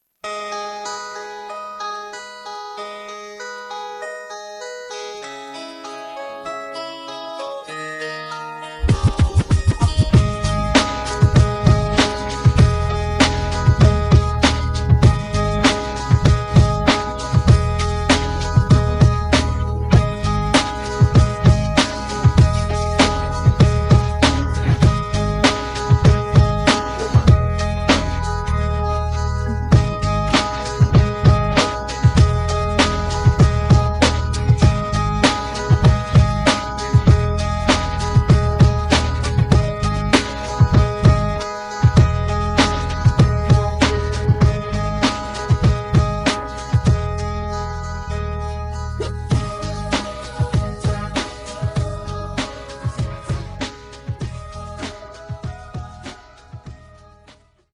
음정 -1키 3:07
장르 가요 구분 Voice MR